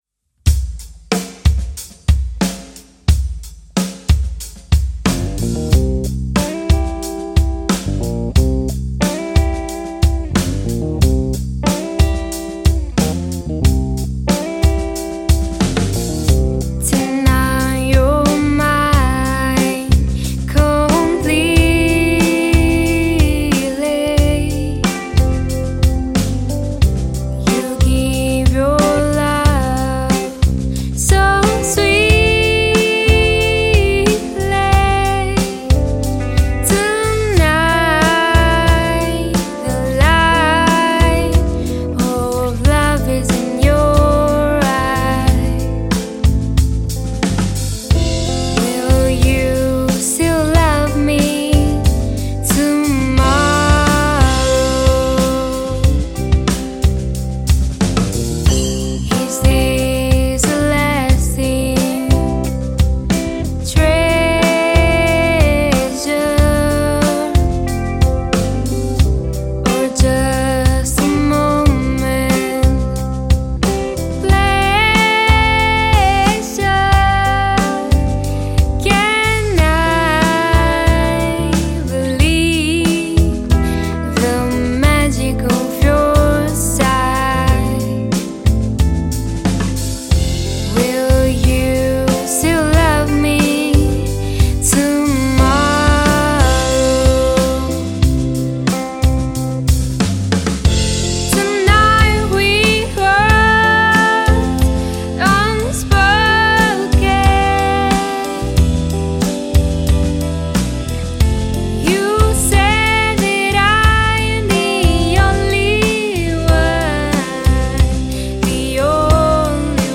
VOCE
CHITARRA E BASSO
BATTERIA